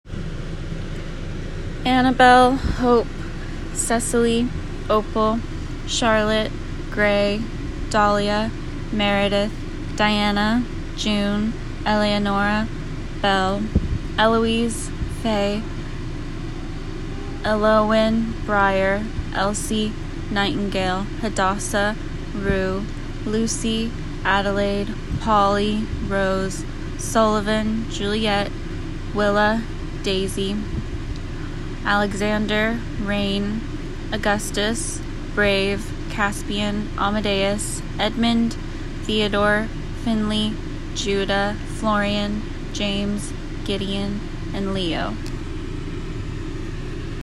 (the pause was because sirens went by & I was trying to figure out what the sound was :joy:)